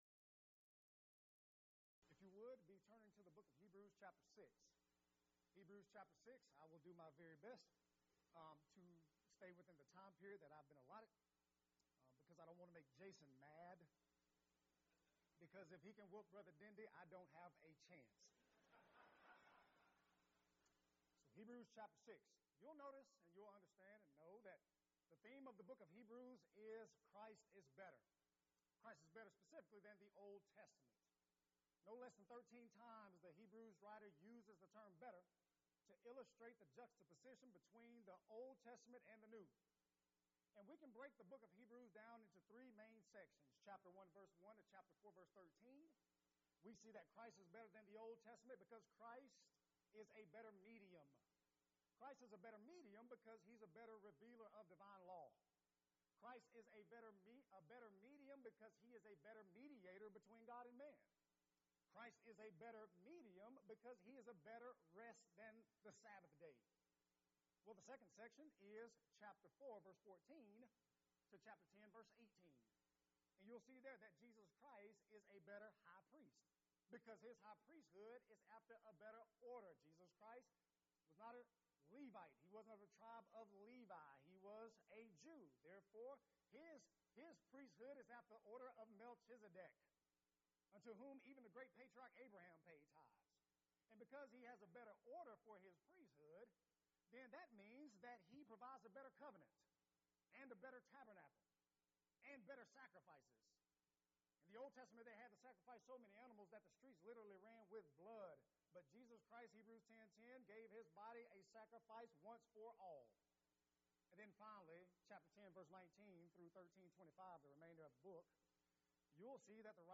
Event: 4th Annual Men's Development Conference Theme/Title: Wait on the Lord